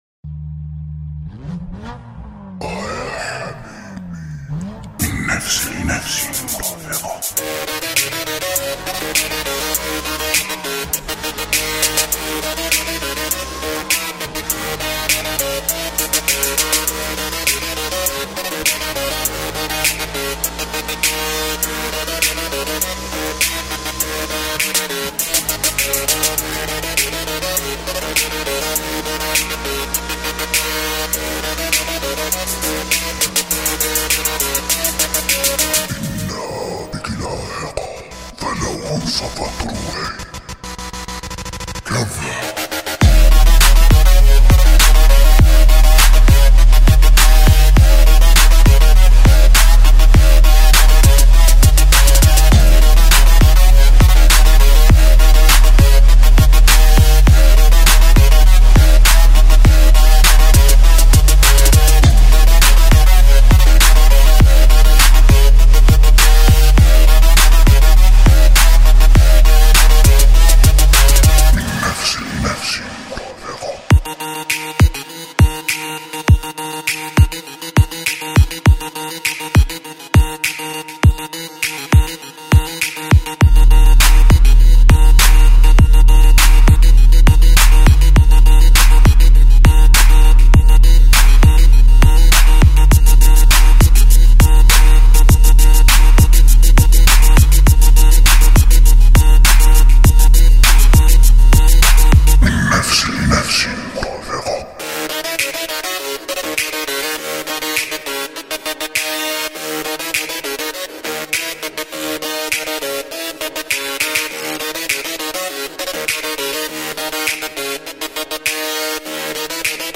arabian_trap_music_desert_trap_mixmp3ritm_top.mp3